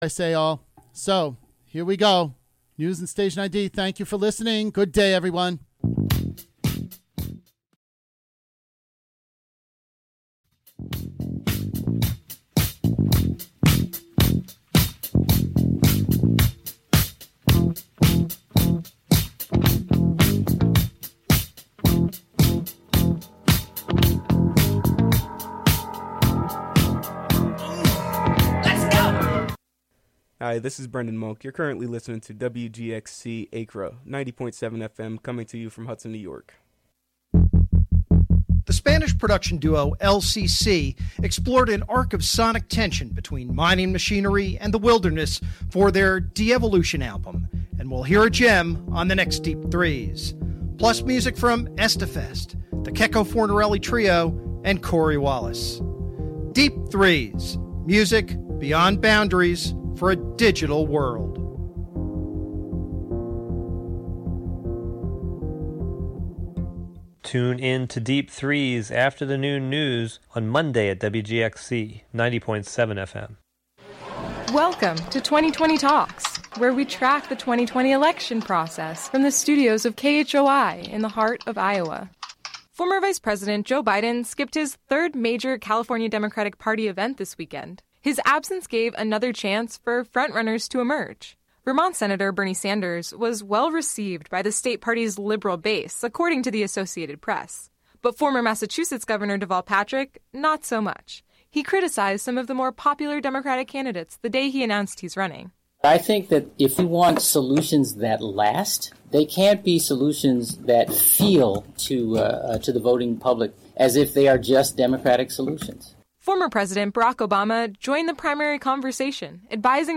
Classic Rock: Nov 18, 2019: 11am - 11:59 am